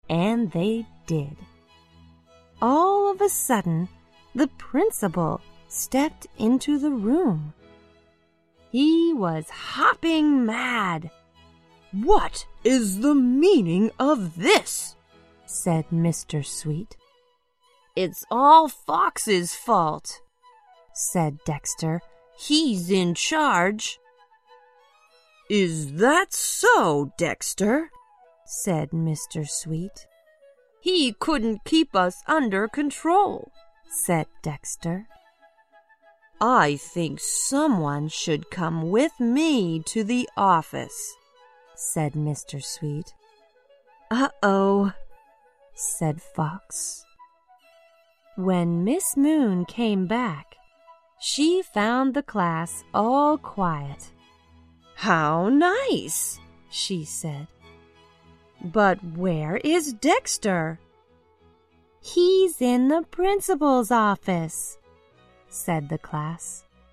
在线英语听力室小狐外传 第41期:生气的听力文件下载,《小狐外传》是双语有声读物下面的子栏目，非常适合英语学习爱好者进行细心品读。故事内容讲述了一个小男生在学校、家庭里的各种角色转换以及生活中的趣事。